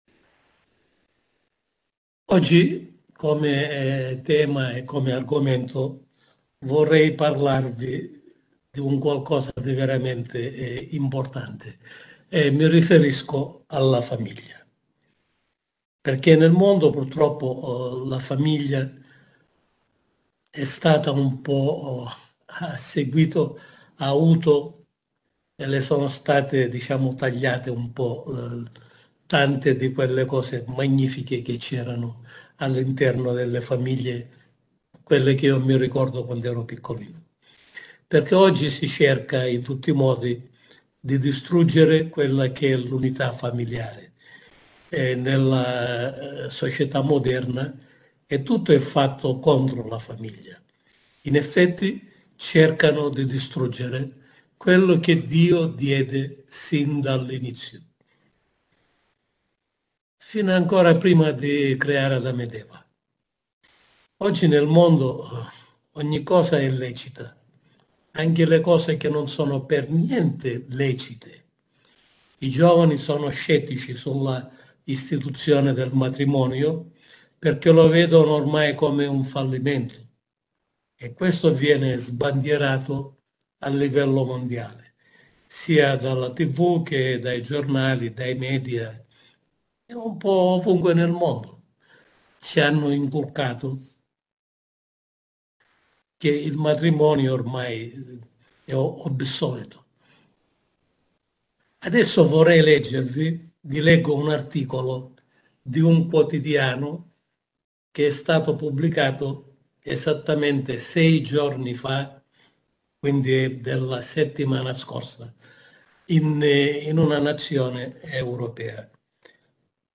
Sermone pastorale